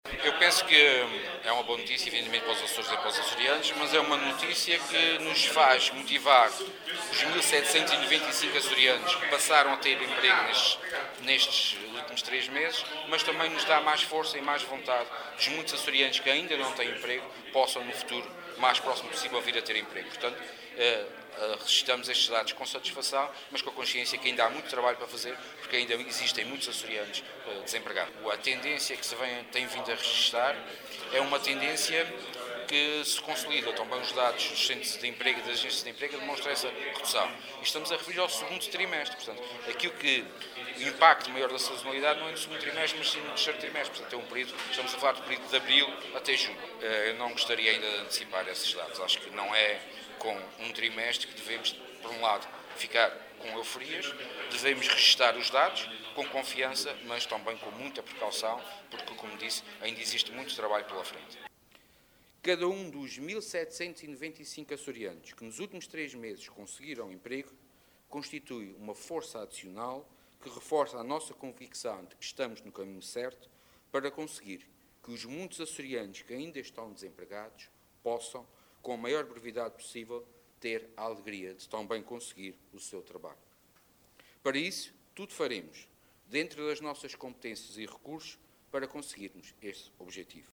O Vice-Presidente do Governo dos Açores disse hoje, em Ponta Delgada, que a redução da taxa de desemprego na Região nos últimos três meses é “uma boa notícia para os Açores e para os Açorianos”.
Sérgio Ávila, que falava à margem da cerimónia de assinatura de um protocolo com instituições bancárias, frisou que não é com um trimestre que se deve entrar em “euforias”,  defendendo que se deve “registar os dados com confiança e com muita precaução, porque ainda existe muito trabalho pela frente”.